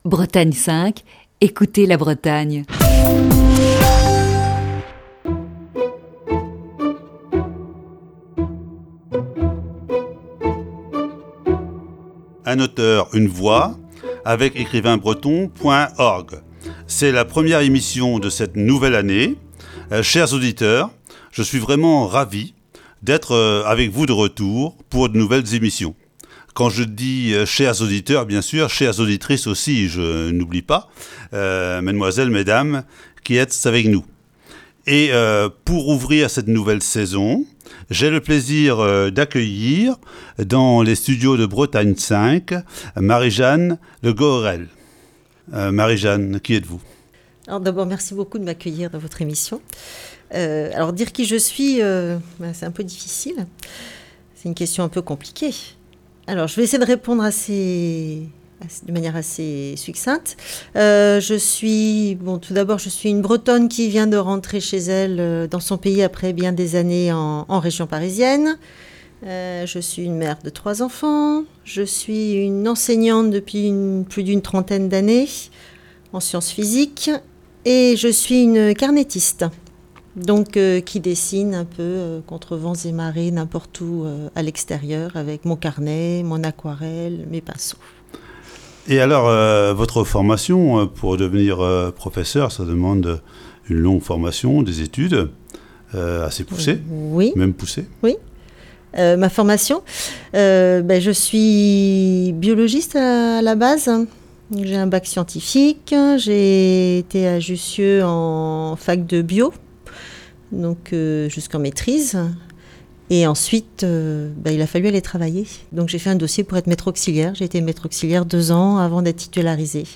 Voici, ce lundi, la première partie de cet entretien.